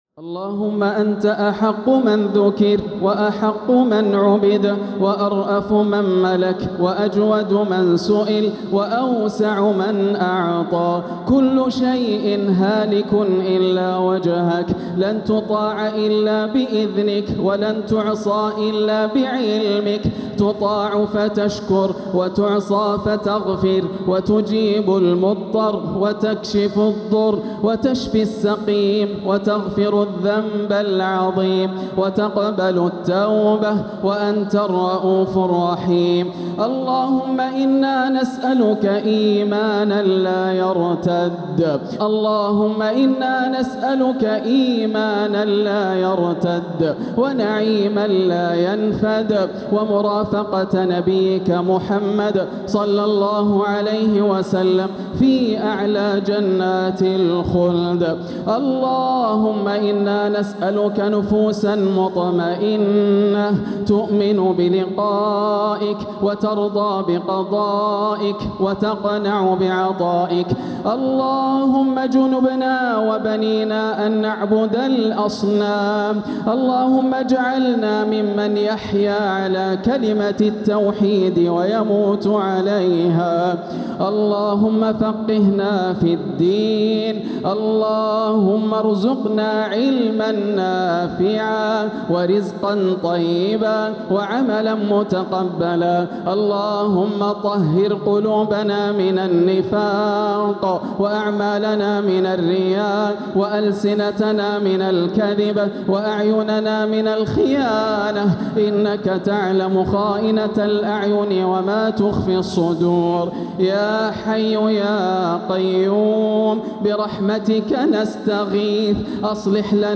دعاء القنوت ليلة 18 رمضان 1447هـ > الأدعية > رمضان 1447 هـ > التراويح - تلاوات ياسر الدوسري